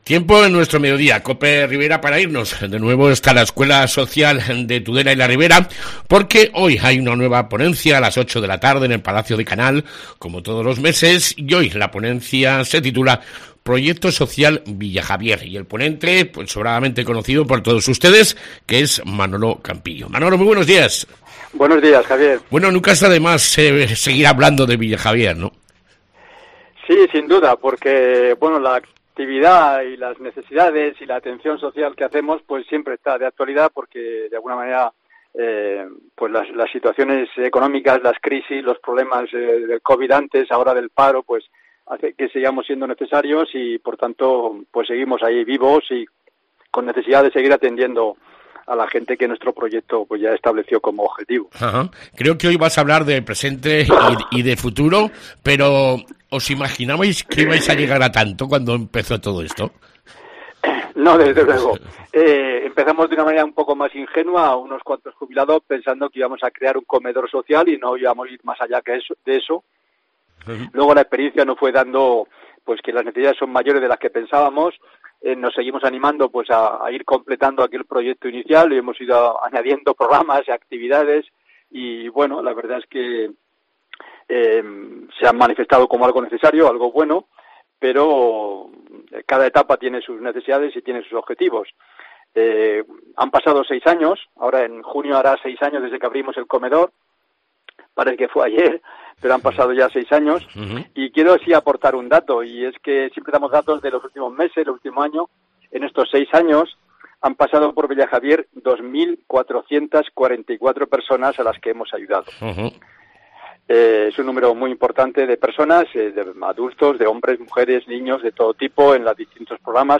Entrevista escuela social de Tudela y la Ribera